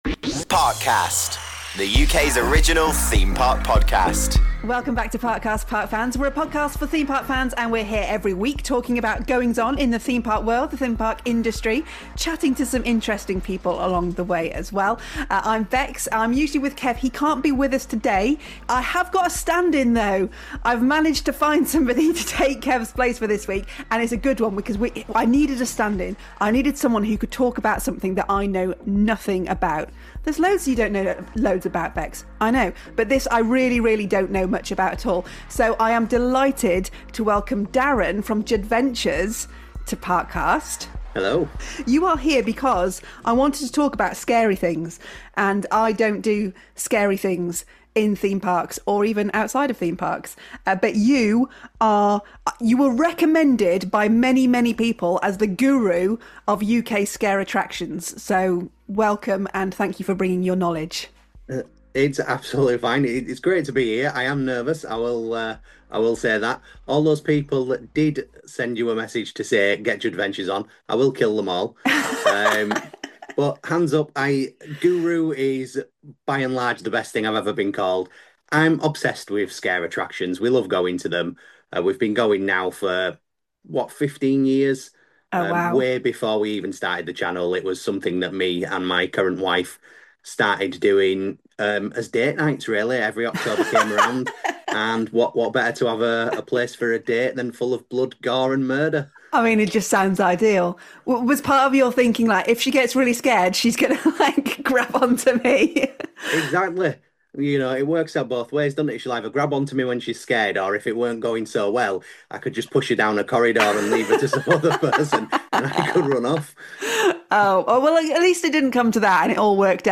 The Rubin Museum of Himalayan Art presents a weekly meditation for beginners and skilled meditators alike. Each episode is inspired by a different work of art from the Museum’s collection and is led by a prominent meditation teacher. The episode begins with an opening talk followed by a 20-minute meditation.